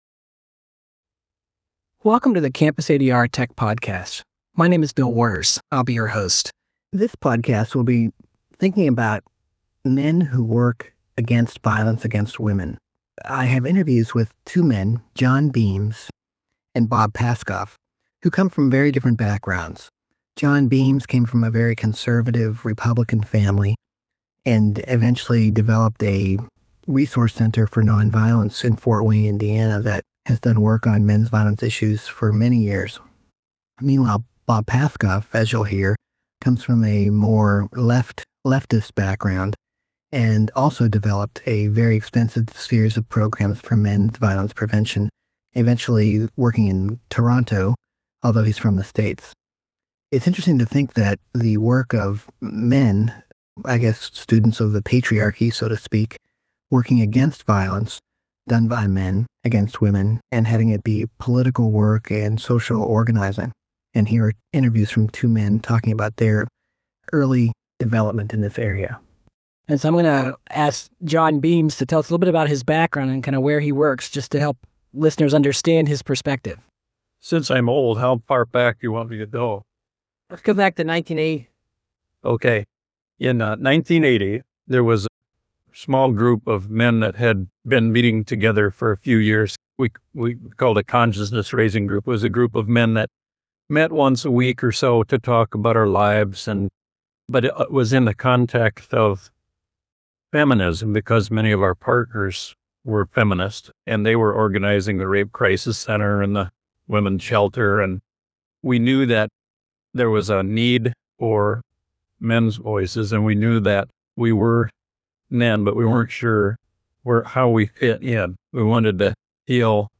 This podcast recording was done at the 2005 Roots to Wings Battering Intervention conference held in Dearborn Michigan in early November.
Before posting it here, I ran it through at AI audio cleaning app to remove some of the background sounds from the hotel lobby. Unfortunately for me perhaps, I come out sounding more like a teenager with a potential speech impediment, but the interview subjects sound good and the distracting background noises were completely removed.